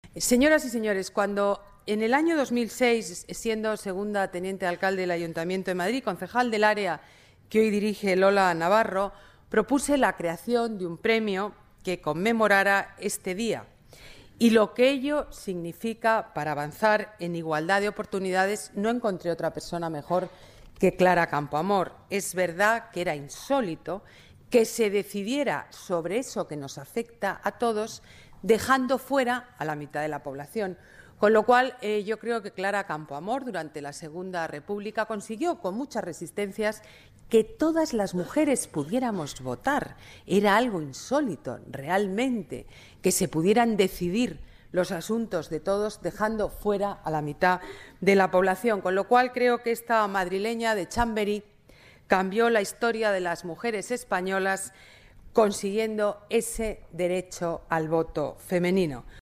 Más archivos multimedia Declaraciones de la alcaldesa sobre la ganadora del premio Clara Campoamor, Isabel Gemio Declaraciones de la alcaldesa sobre el voto femenino Celebración del Día Internacional de la Mujer